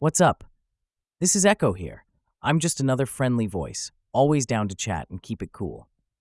NavTalk 提供多种高质量的语音合成风格，您可以通过 voice 参数自由选择数字人音色：
有混响的演绎音